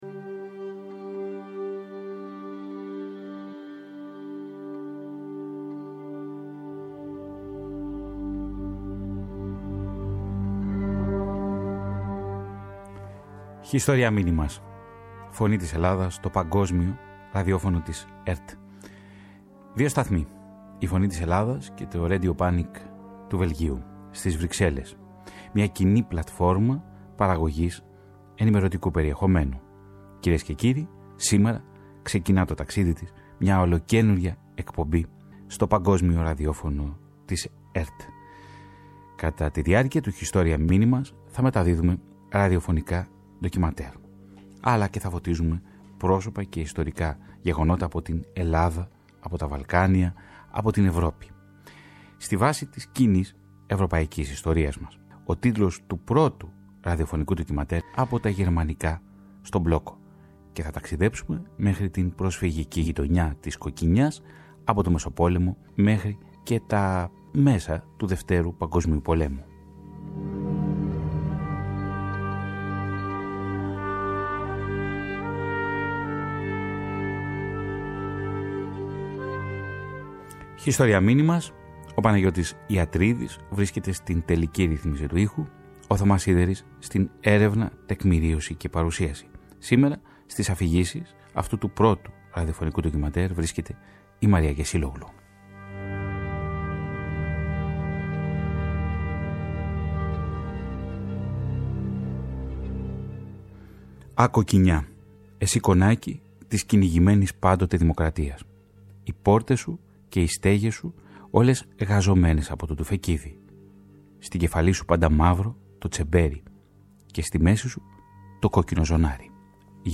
Το νέο ραδιοφωνικό ντοκιμαντέρ της σειράς HISTORIAS MINIMAS, σε συνεργασία με το RADIO PANIK του Βελγίου, αναφέρεται στην προσφυγική εγκατάσταση στην περιοχή της Κοκκινιάς και πώς βίωσαν οι νέοι σε ηλικία πρόσφυγες τη ναζιστική κατοχή.